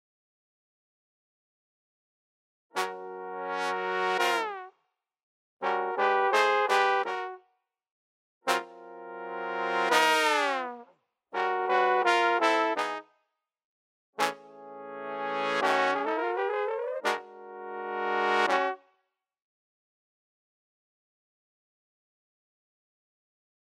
Heres the same file with Straight Ahead Samples.
All with Smart Delay, very slight stereo panning and very slight room reverb. Compressor and Limiter on the Master
Trombones: